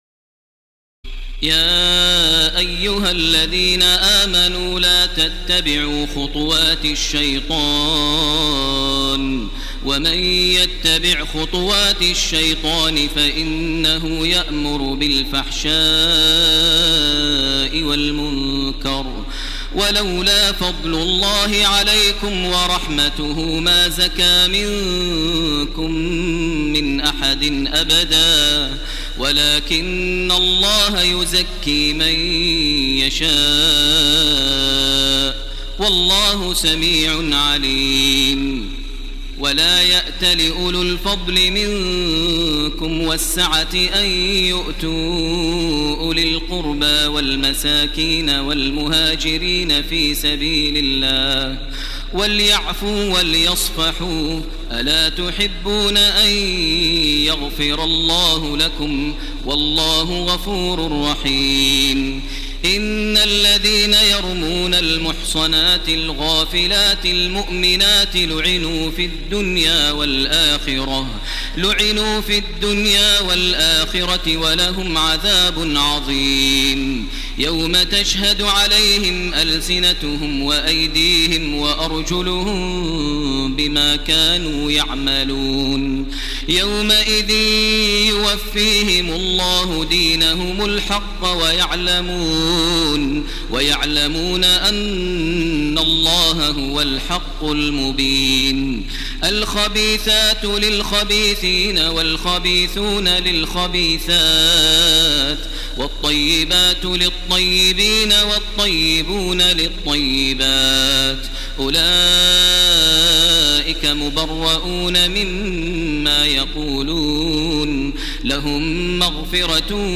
تراويح الليلة الثامنة عشر رمضان 1430هـ من سورتي النور (21-64) و الفرقان (1-20) Taraweeh 18 st night Ramadan 1430H from Surah An-Noor and Al-Furqaan > تراويح الحرم المكي عام 1430 🕋 > التراويح - تلاوات الحرمين